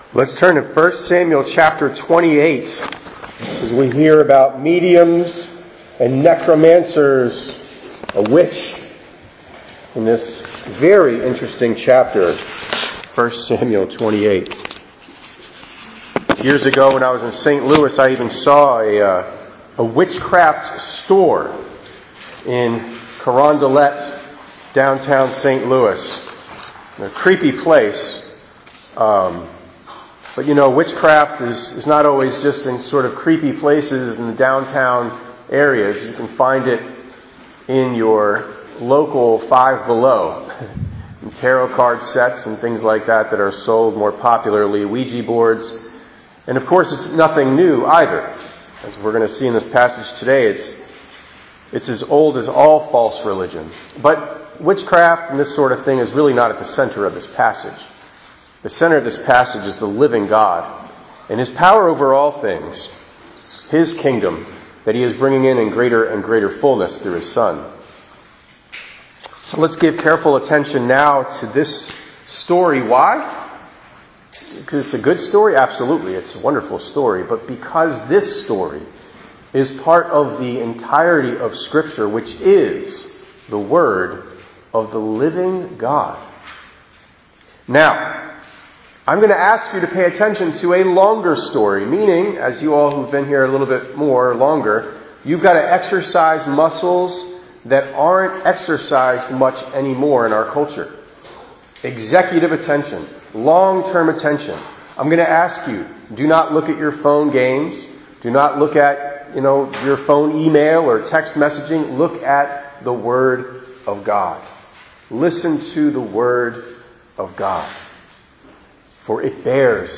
3_29_26_ENG_Sermon.mp3